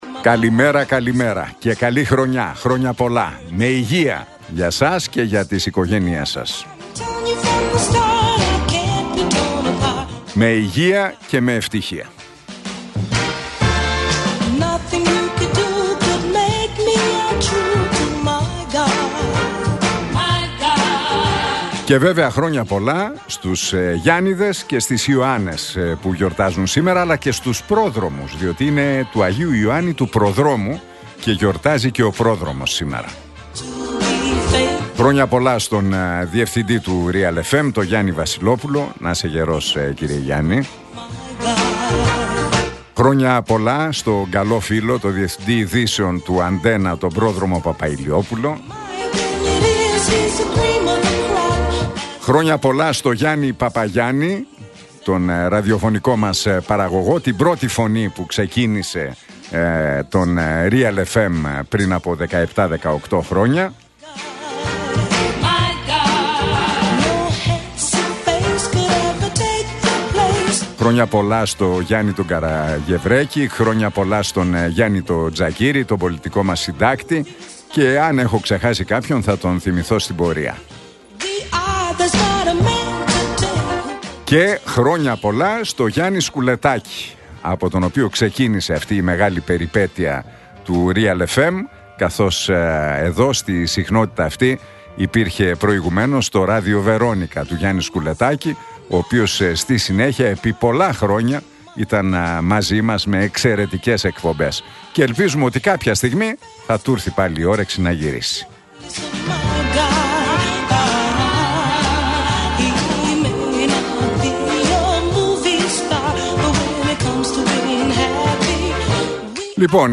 Ακούστε το σχόλιο του Νίκου Χατζηνικολάου στον ραδιοφωνικό σταθμό RealFm 97,8, την Τρίτη 7 Ιανουαρίου 2025.